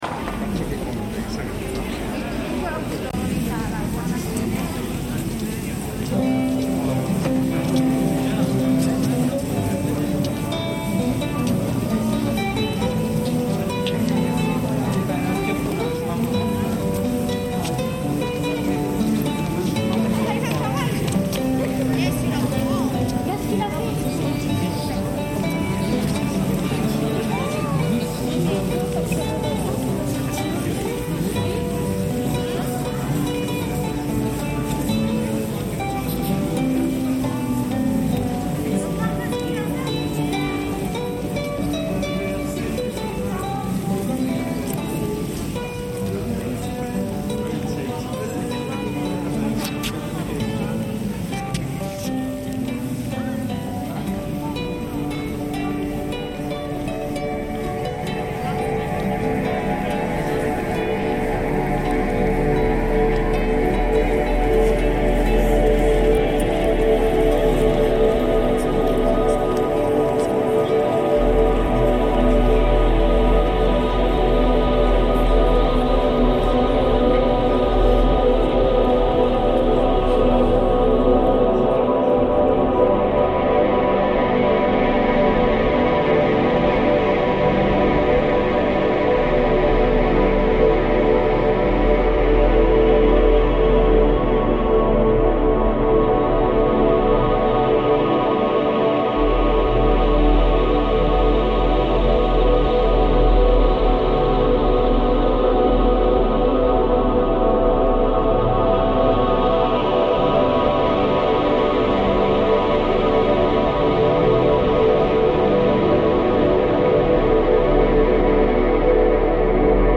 Venetian busker reimagined